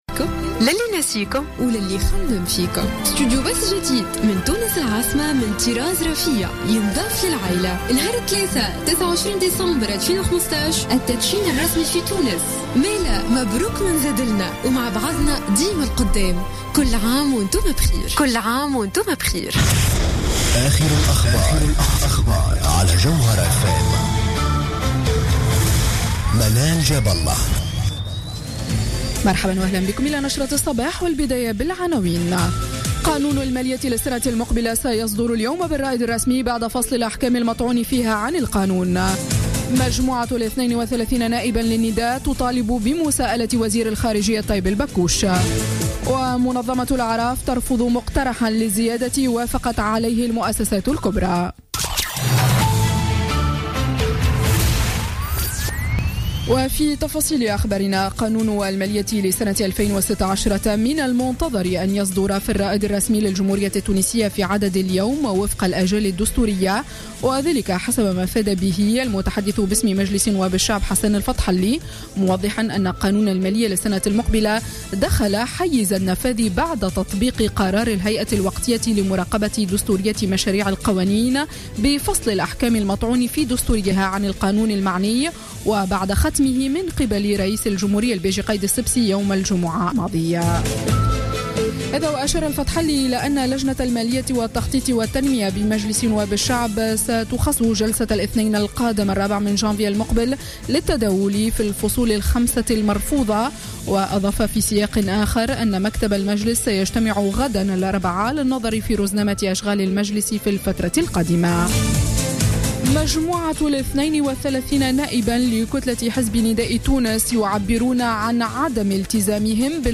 نشرة أخبار السابعة صباحا ليوم الثلاثاء 29 ديسمبر 2015